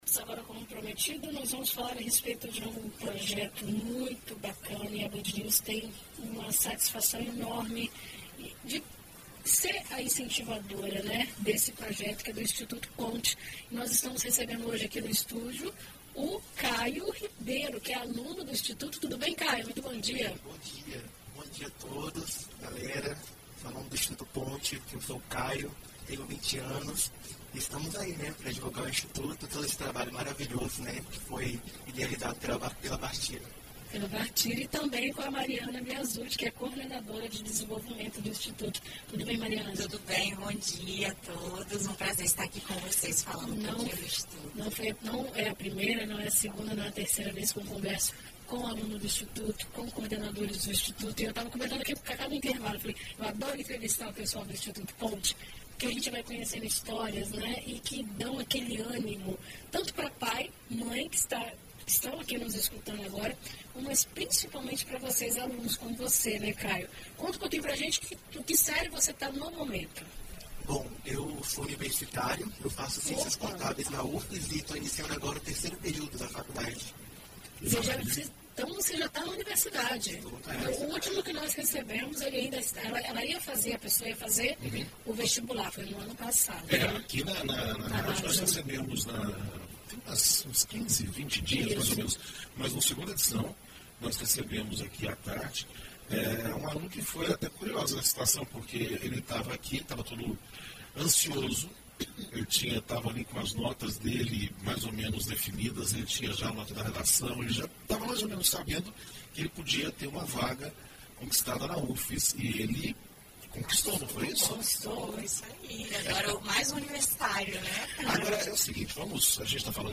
O Instituto Ponte identifica estudantes talentosos do 7º ano do ensino fundamental II ao 2º ano do ensino médio, de escolas públicas, e lhes concedem oportunidades educacionais e acesso a atividades de desenvolvimento dos aspectos acadêmicos e socioemocionais, do ensino fundamental à universidade. Em entrevista a BandNews FM ES